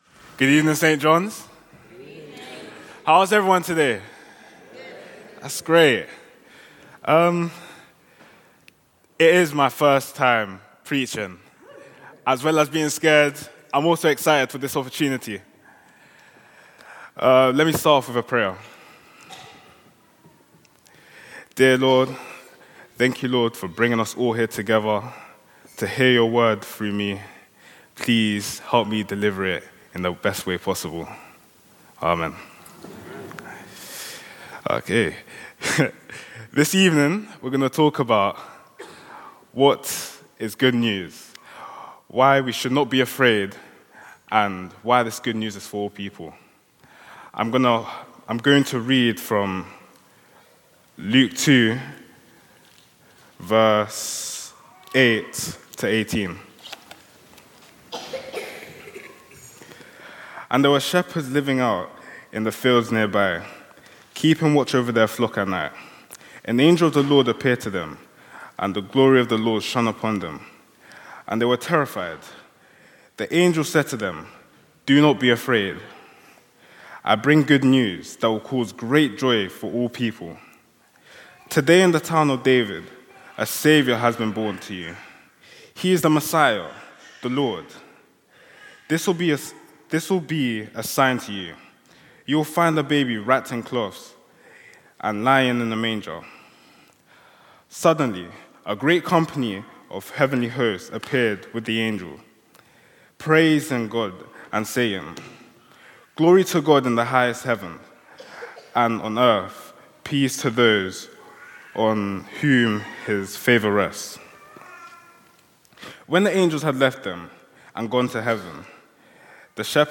From Service: "Youth Led Service"